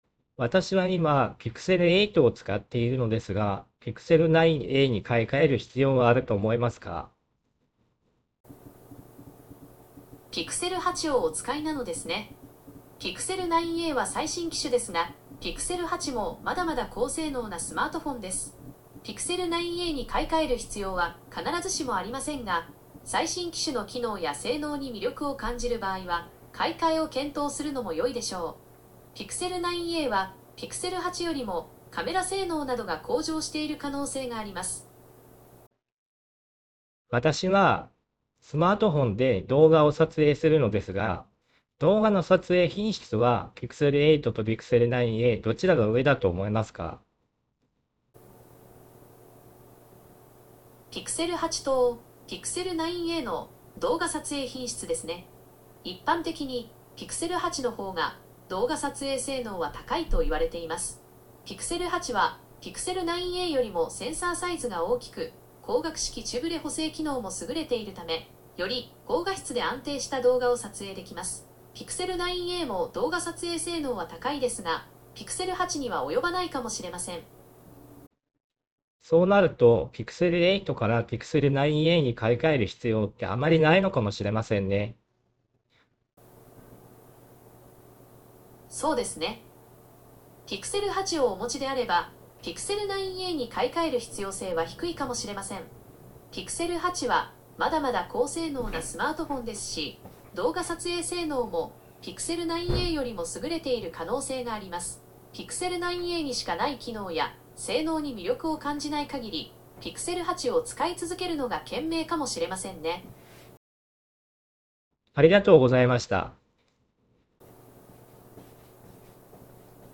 ※私の声はボイスチェンジャーを使って加工しています
※音量は適宜調整しています